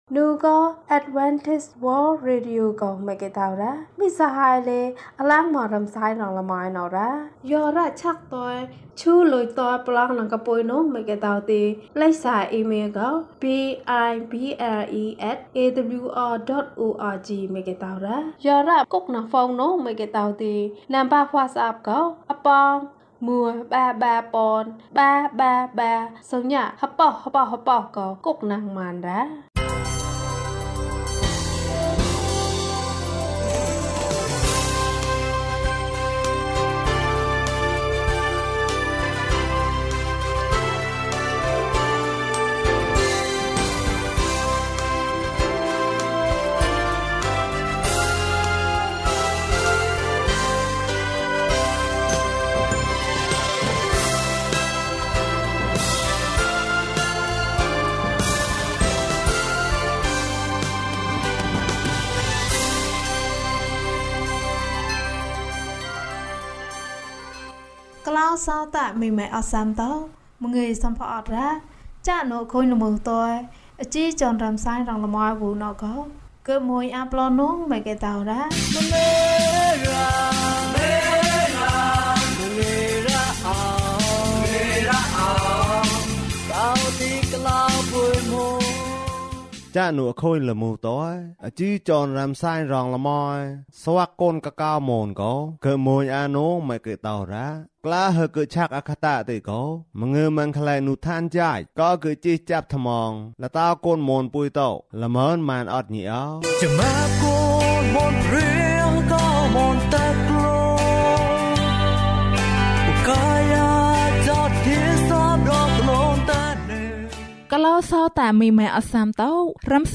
သခင်ယေရှုက ကျွန်ုပ်ကို ပို့ဆောင်ပါ။ ကျန်းမာခြင်းအကြောင်းအရာ။ ဓမ္မသီချင်း။ တရား‌ဒေသနာ။